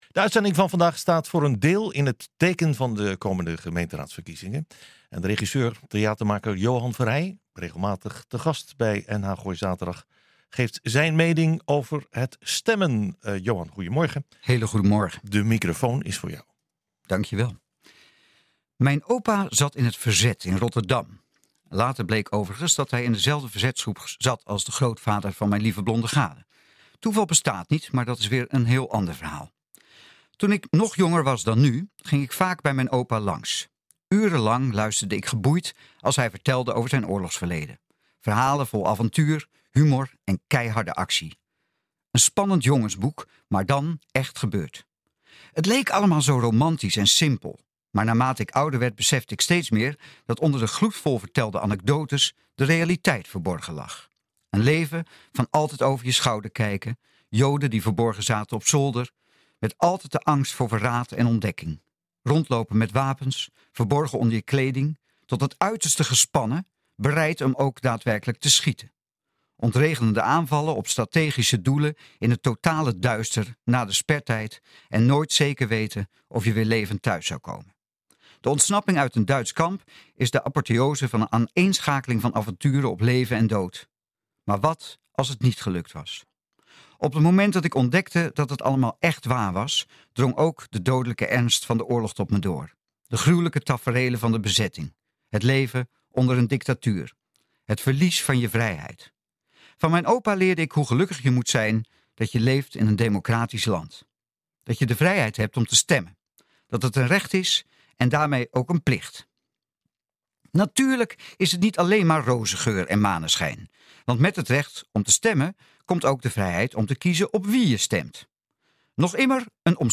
NH Gooi Zaterdag - Column